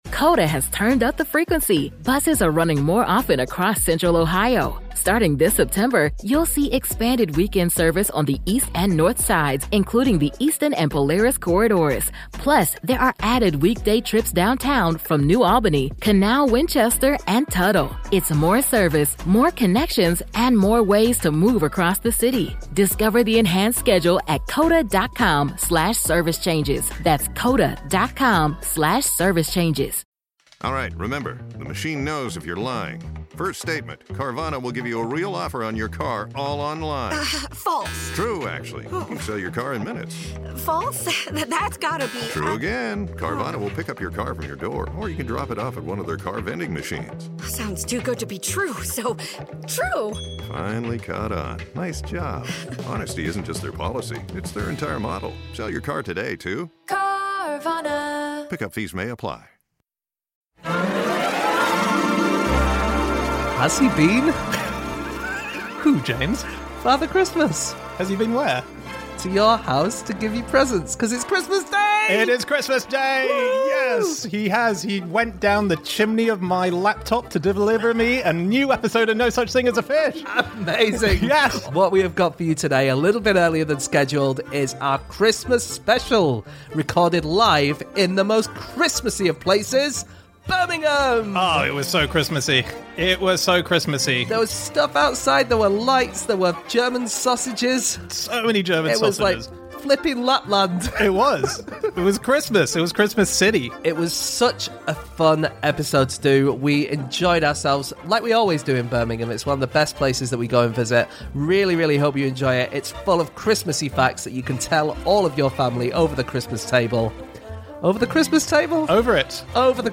Live from Birmingham